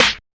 PHANTOM MENACE SNARE.wav